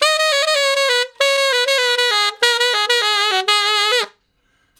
068 Ten Sax Straight (Ab) 05.wav